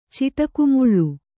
♪ ciṭaku muḷḷu